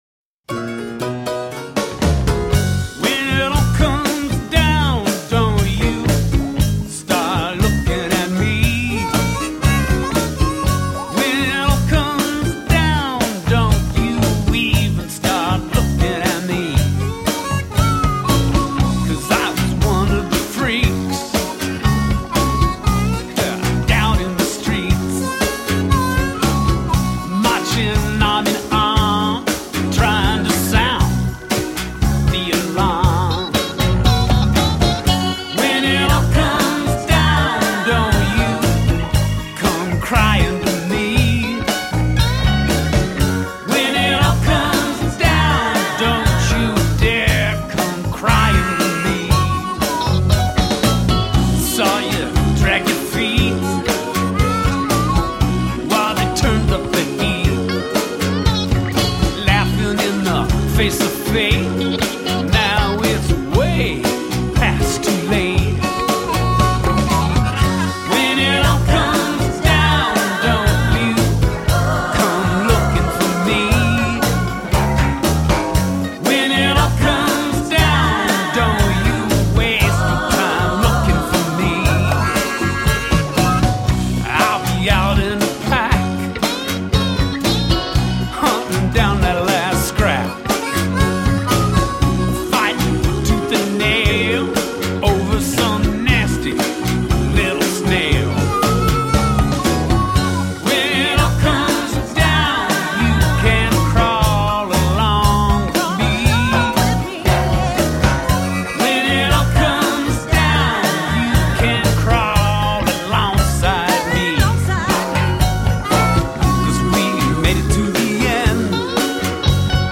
Tagged as: Alt Rock, Rock, Country, Folk